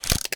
shutter.ogg